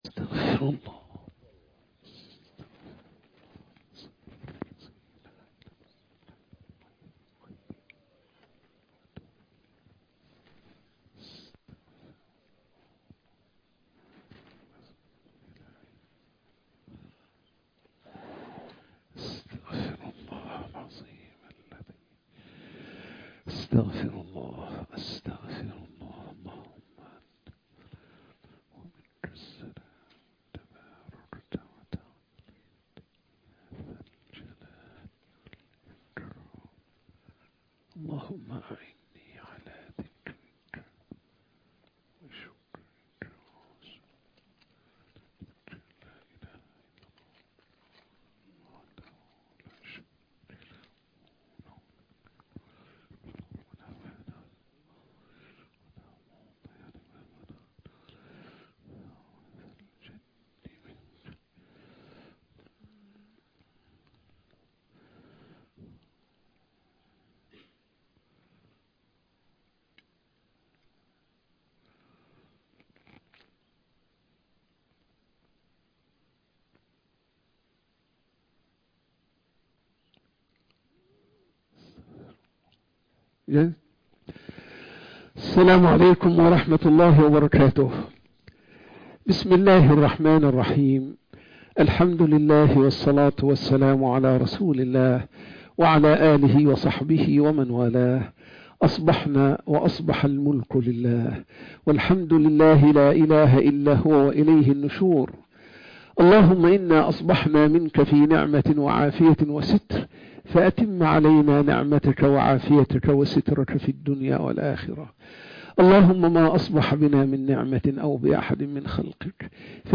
قول نحن فراعنة لا عرب هل يجوز؟ - درس بعد الفجر